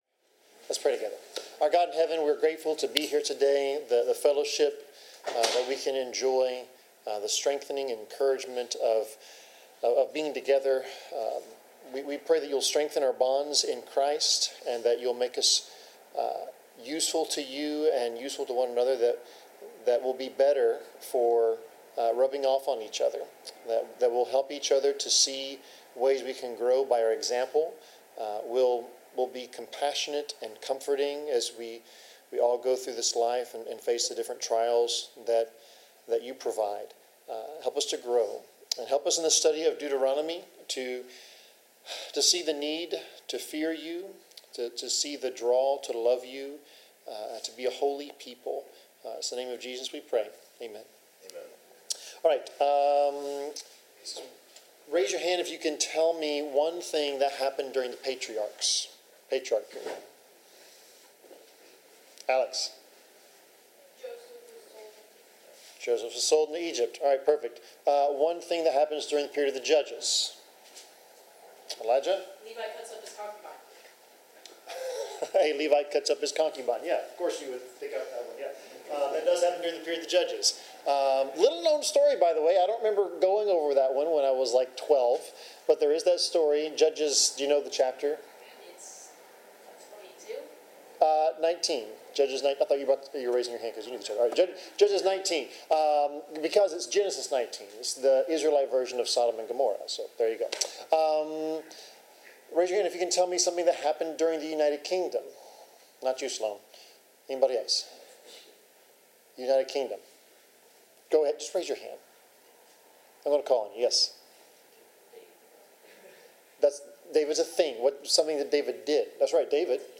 Bible class: Deuteronomy 12-13
Service Type: Bible Class Topics: Battling sin , Consequences of Sin , Faith , Holiness , Idolatry , Obedience , Wrath of God